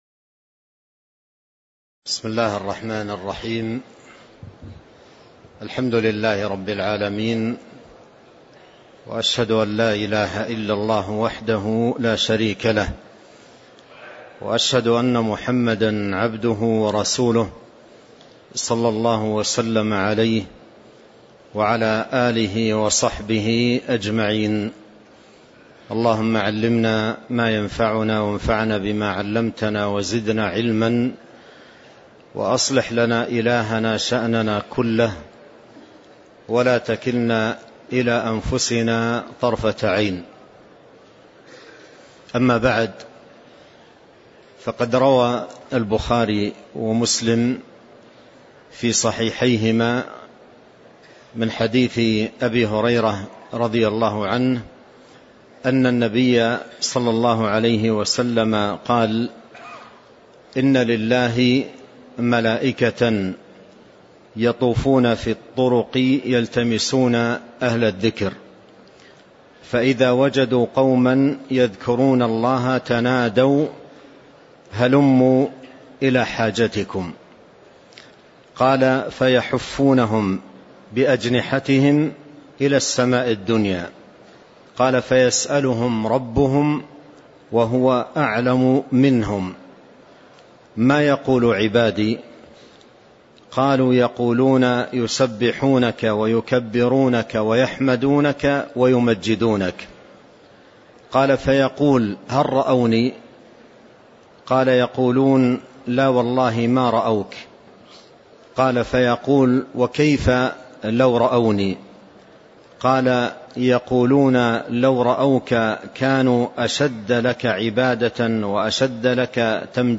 تاريخ النشر ٢٥ ذو الحجة ١٤٤٣ هـ المكان: المسجد النبوي الشيخ